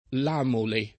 [ l # mole ]